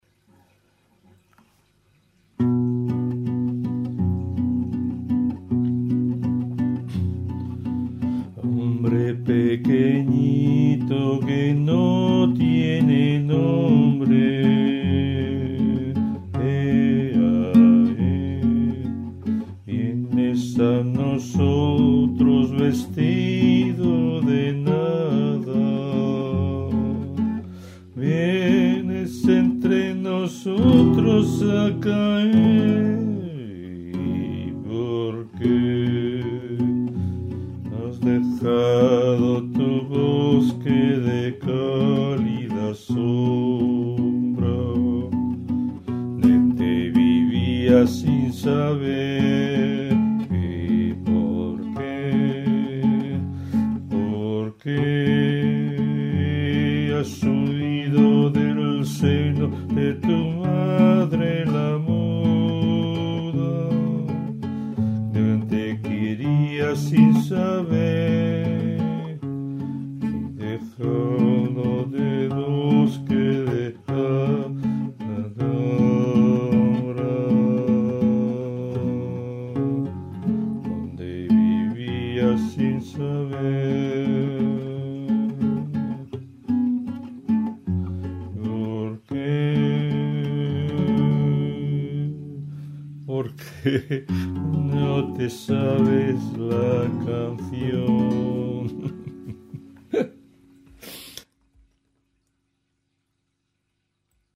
1.1 Hombre peque....mp3   1/21 canción 4/4                  1.1 Hombre peque....mp3
1.2 Ya esta II.mp3               2/22 melopeya T8tc            1.2 Ya esta II.mp3     P.2      Ya esta aqui P.2.3.4